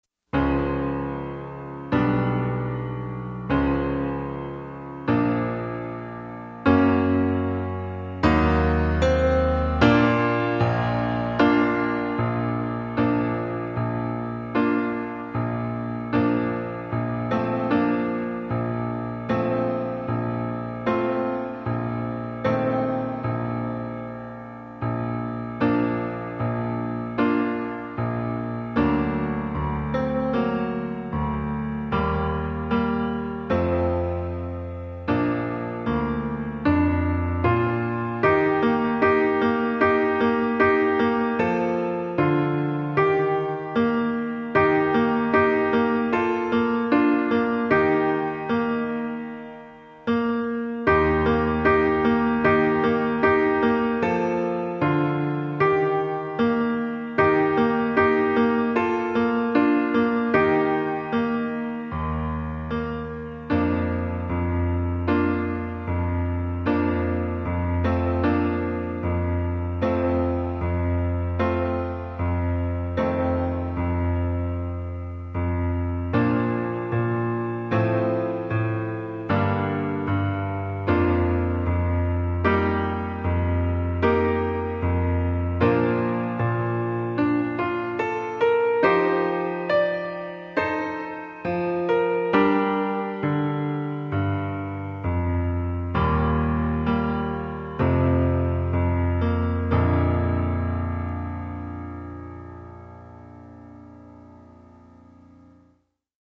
TROMBA SOLO • ACCOMPAGNAMENTO PIANO + BASE MP3
Trombone